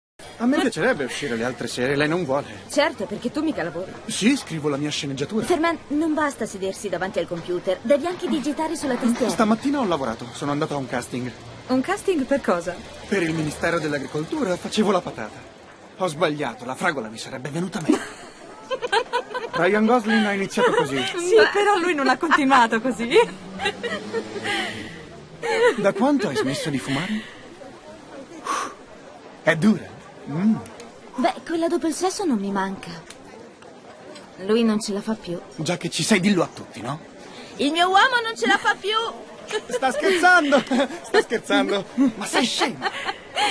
in cui doppia Philippe Lacheau.